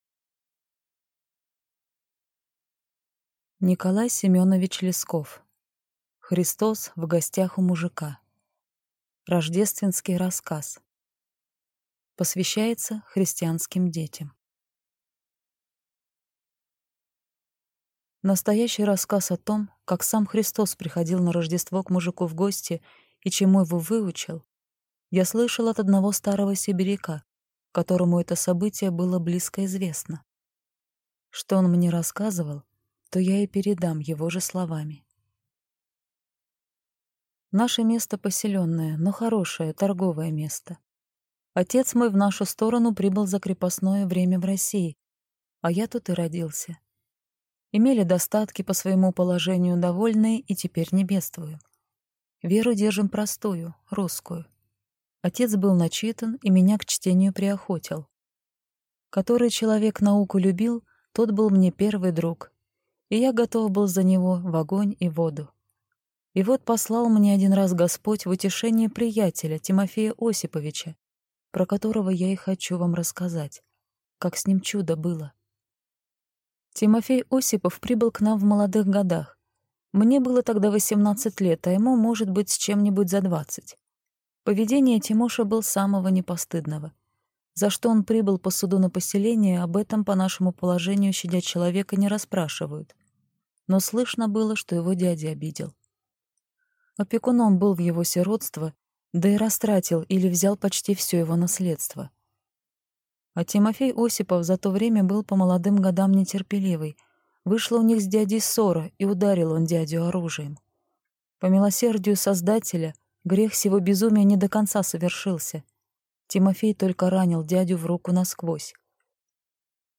Аудиокнига Христос в гостях у мужика | Библиотека аудиокниг